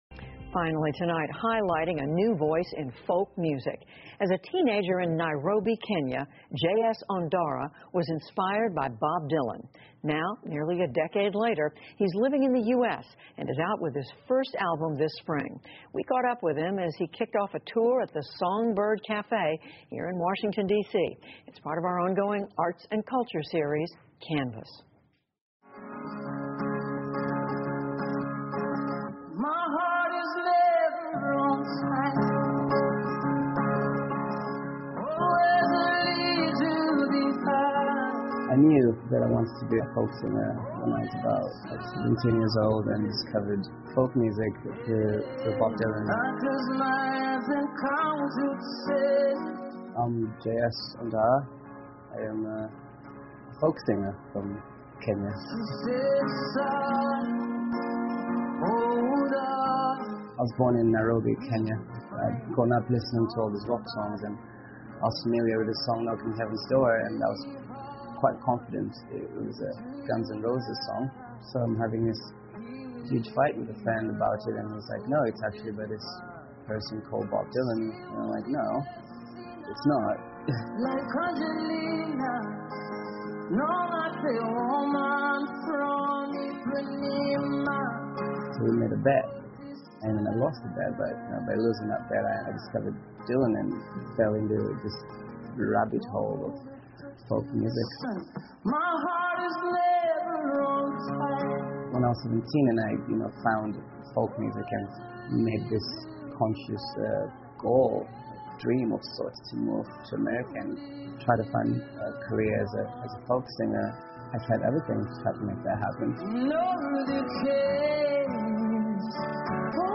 PBS高端访谈:了解民俗音乐人翁达拉 听力文件下载—在线英语听力室